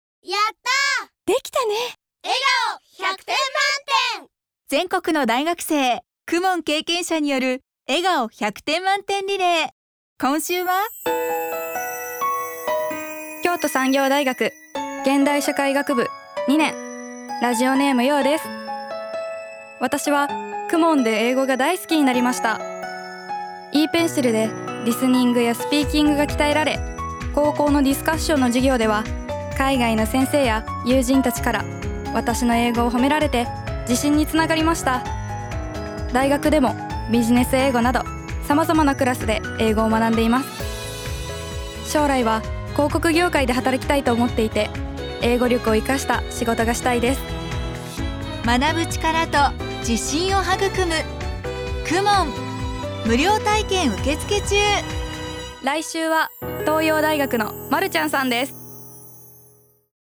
全国の大学生の声